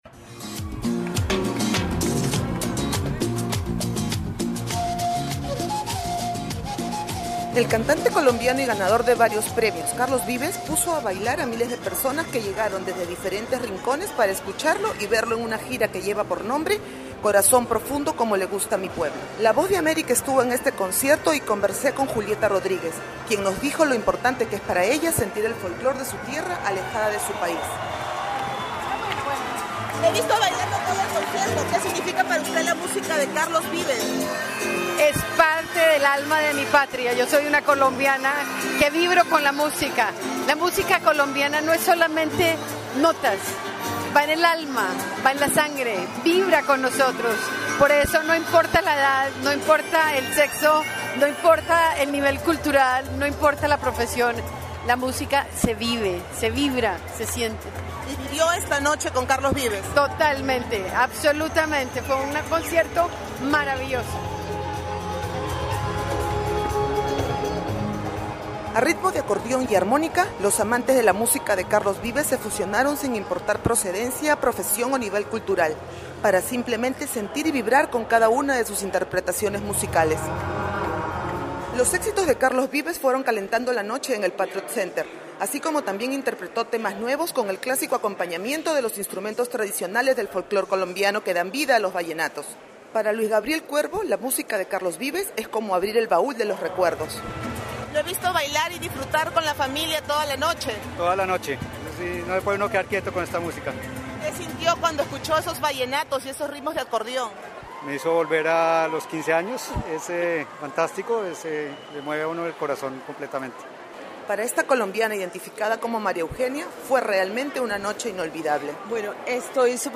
Concierto de Carlos Vives Reportaje